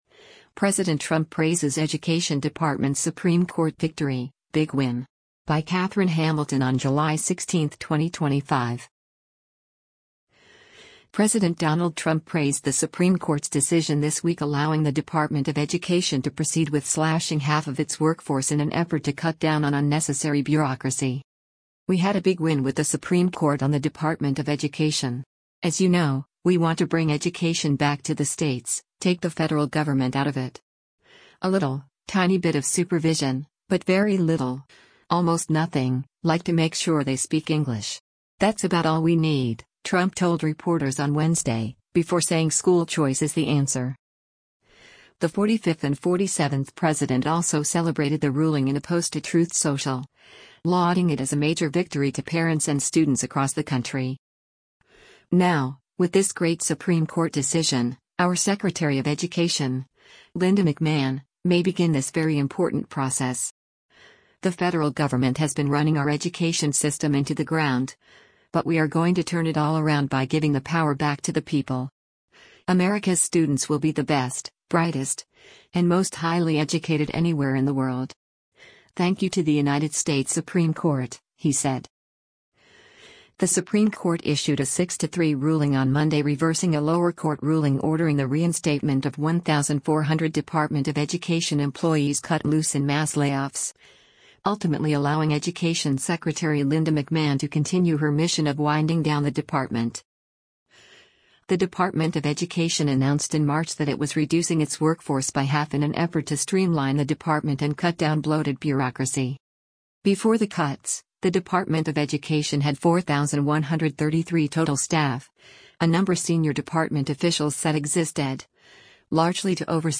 “We had a big win with the Supreme Court on the Department of Education. As you know, we want to bring education back to the states, take the federal government out of it. A little, tiny bit of supervision, but very little, almost nothing, like to make sure they speak English. That’s about all we need,” Trump told reporters on Wednesday, before saying “school choice is the answer.”